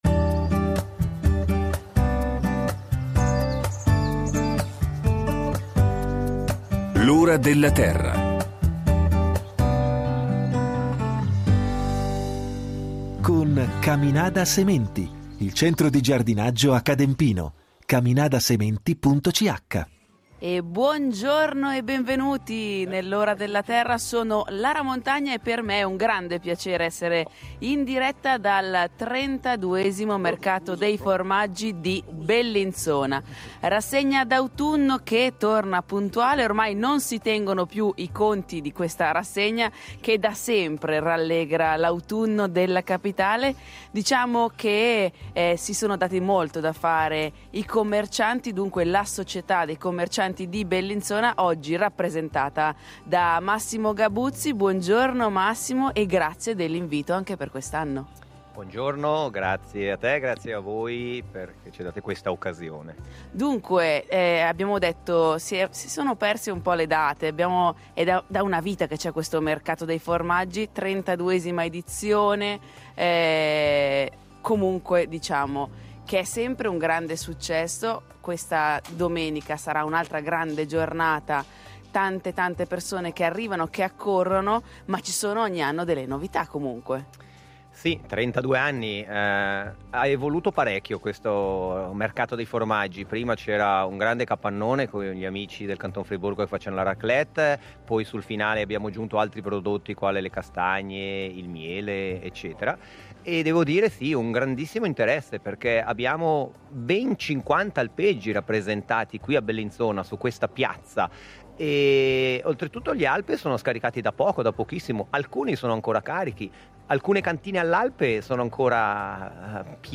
L’Ora della Terra trasmetterà in diretta dal cuore della manifestazione con ospiti in diretta